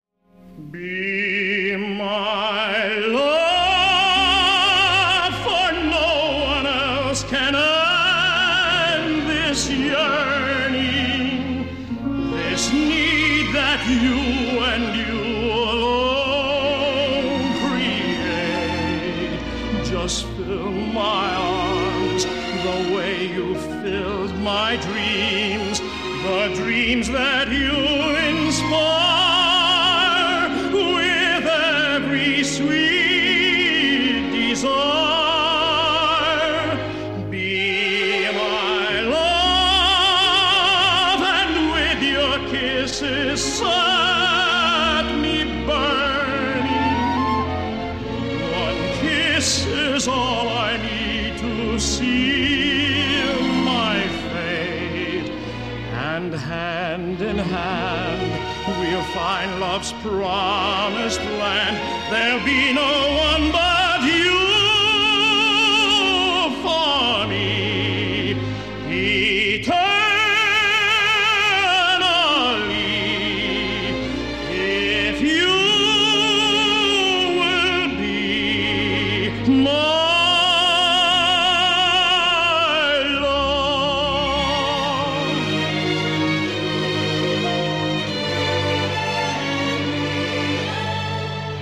итальянский тенор